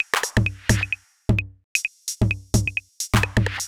130 Driller Killer Perc.wav